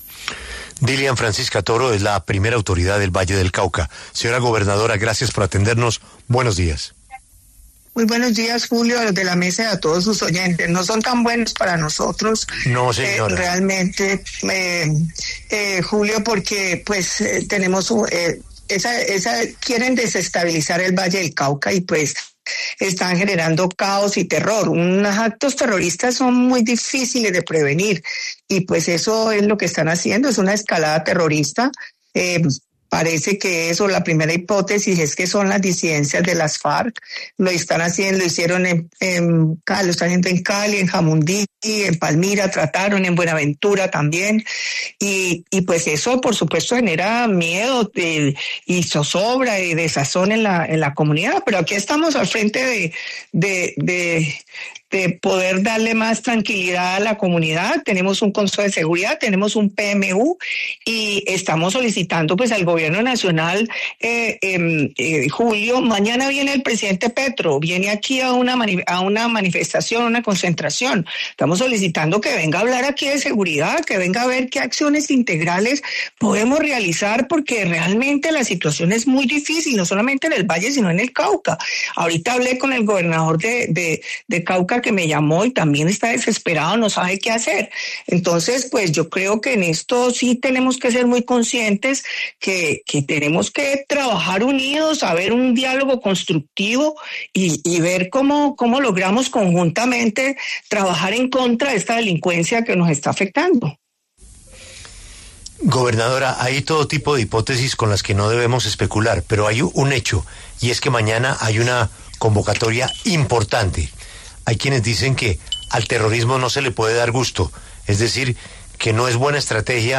La gobernadora del Valle del Cauca, en conversación con La W, hizo un llamado al Gobierno Nacional para que se generen “resultados reales” en materia de seguridad.